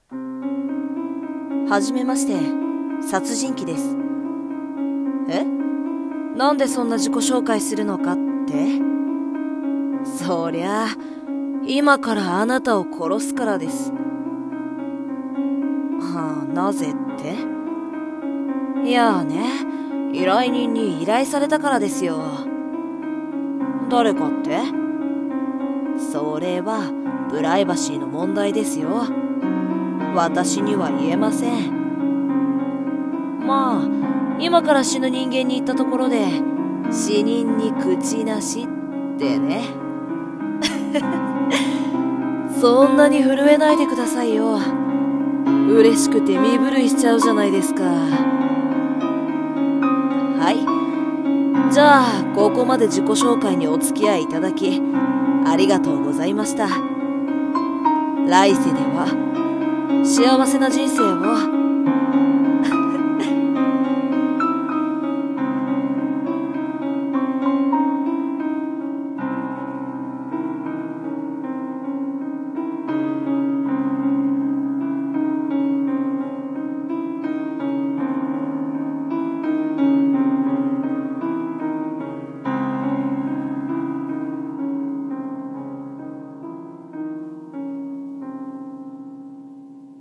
セリフ『殺人鬼』